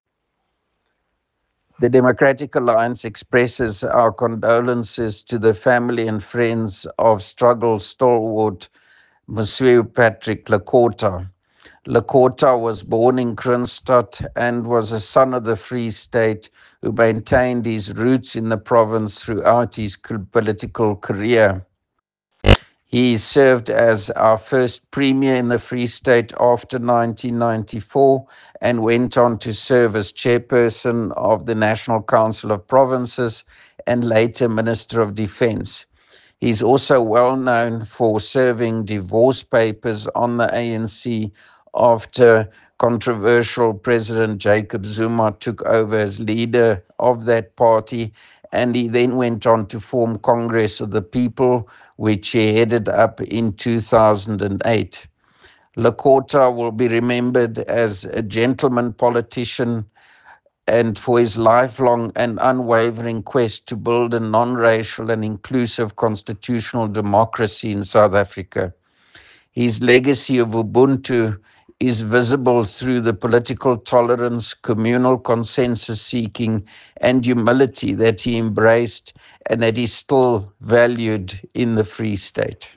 English soundbite by Roy Jankielsohn MPL